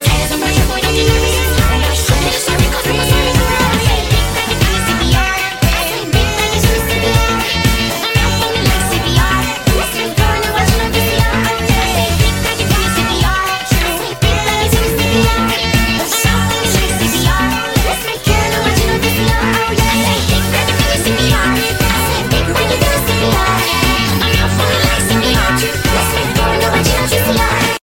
Sped Up Version